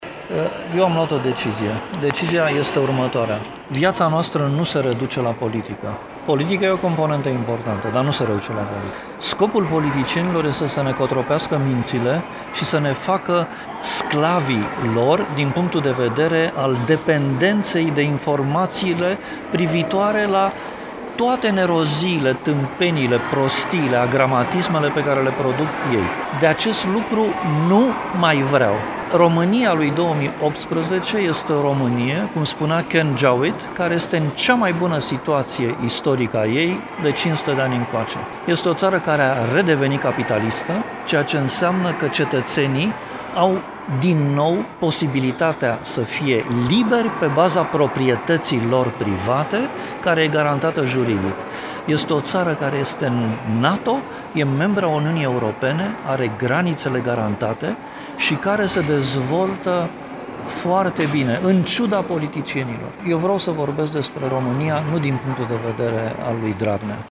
Declarația a fost făcută astăzi la Iași